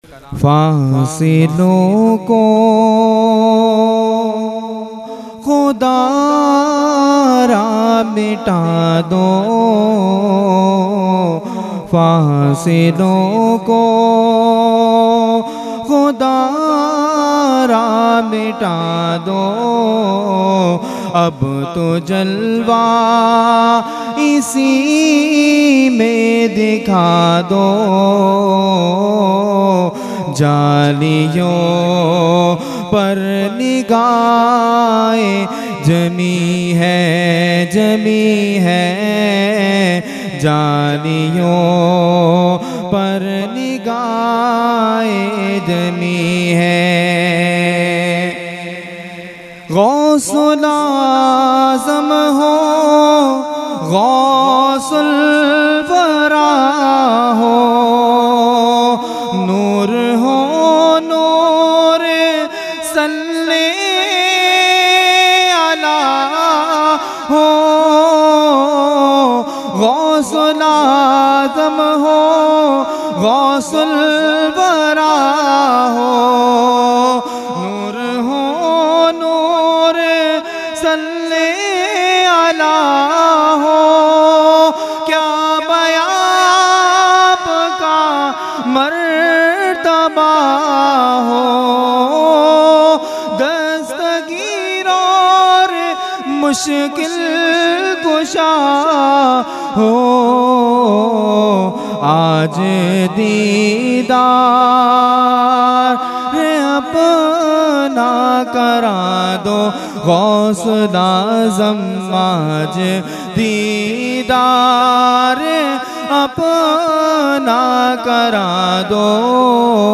Mehfil e 11veen Shareef Held At Dargah Alia Ashrafia Ashrafabad Firdous Colony Karachi Pakistan.
Category : Manqabat | Language : UrduEvent : 11veen Shareef 2024